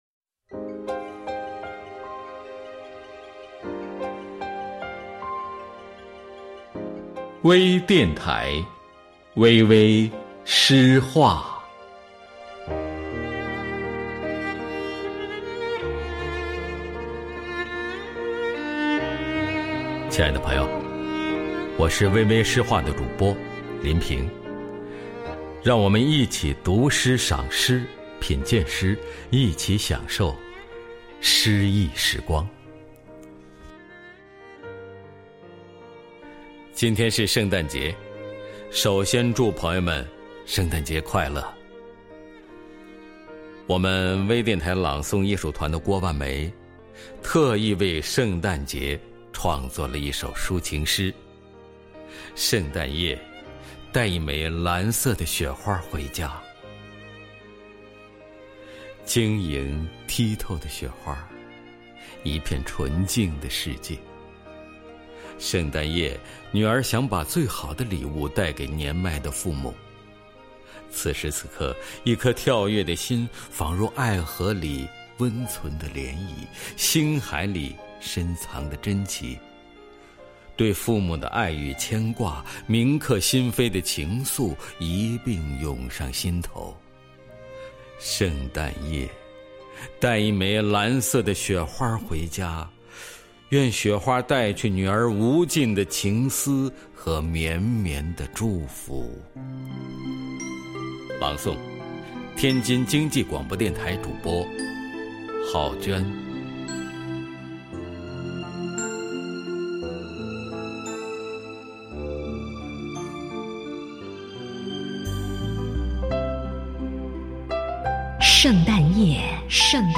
多彩美文  专业诵读
朗 诵 者
配 乐